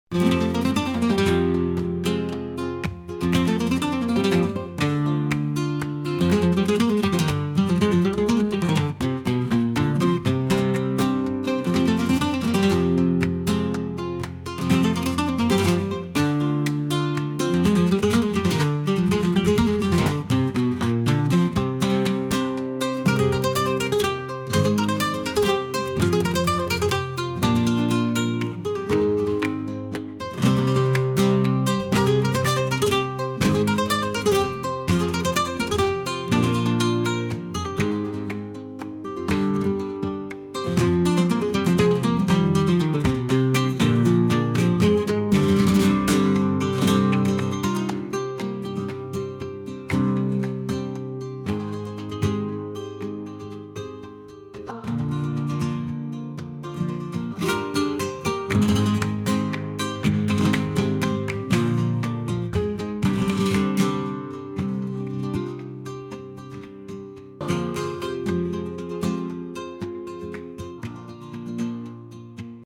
En esta página pueden escuchar algunos de los sonidos del musical flamenco «LA ÚLTIMA ESCLAVA DE GUADÍN».